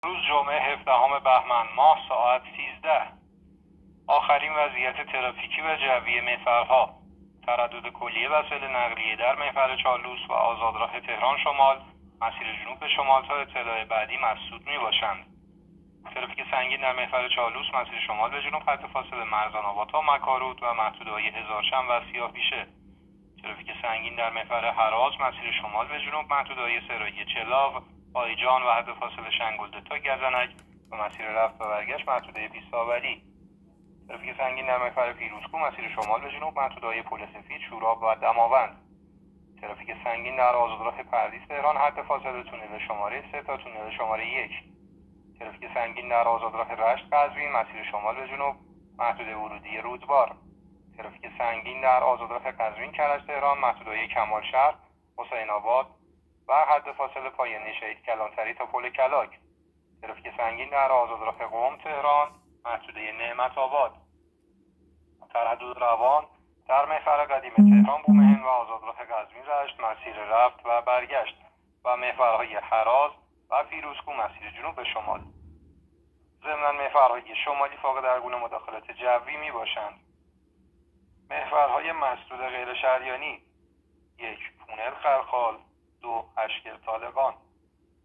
گزارش رادیو اینترنتی از آخرین وضعیت ترافیکی جاده‌ها ساعت ۱۳ هفدهم بهمن؛